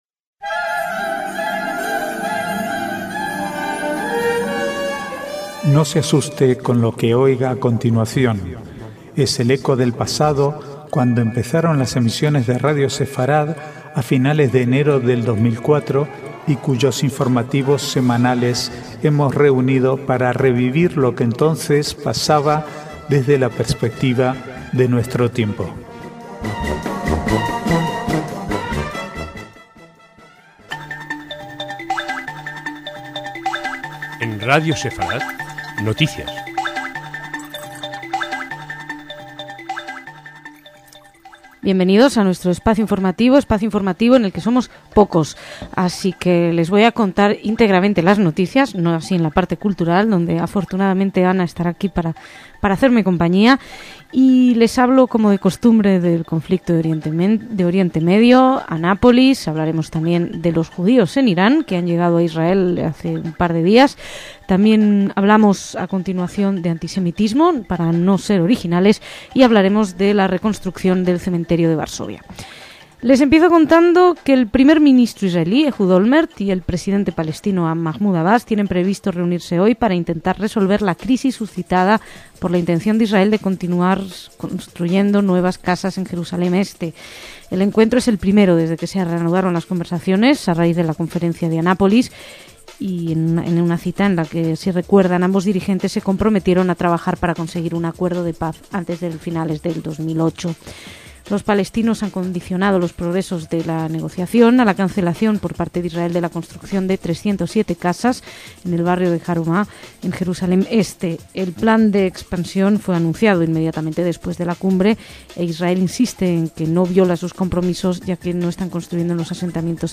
Archivo de noticias del del 27/12/2007 al 3/1/2008